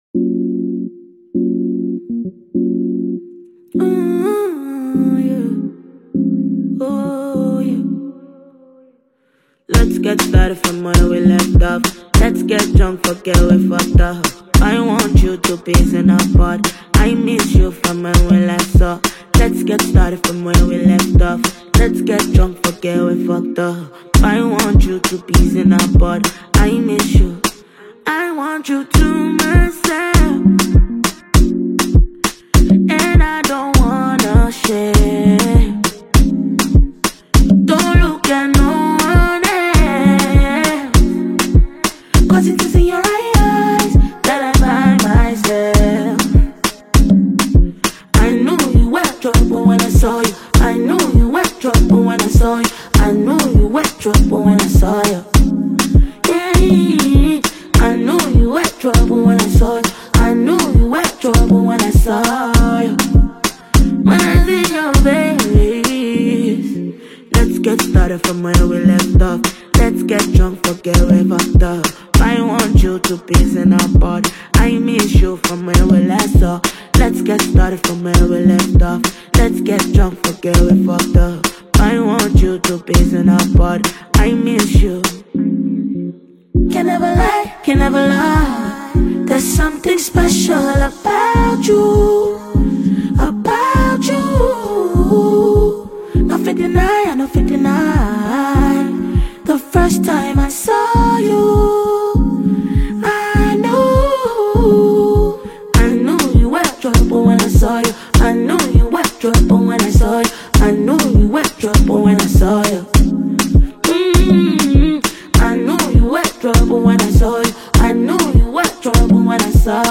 The song is melodious and a masterpiece!